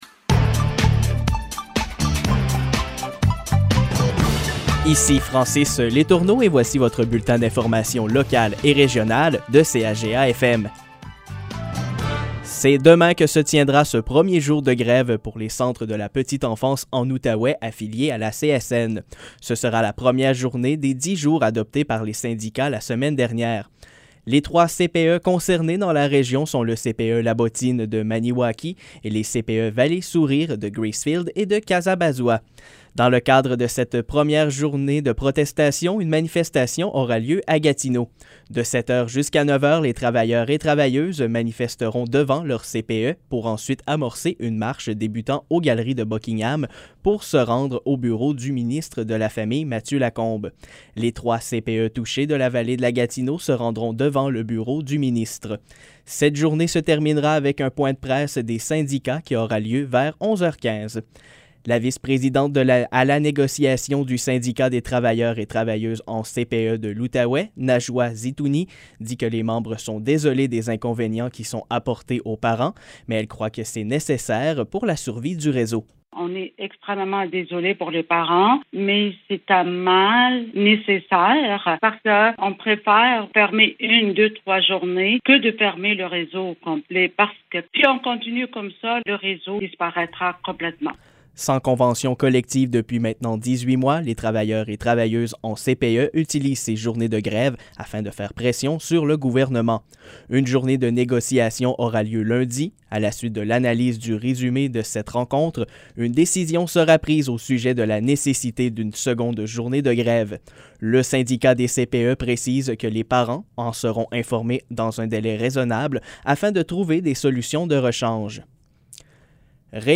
Nouvelles locales - 23 septembre 2021 - 15 h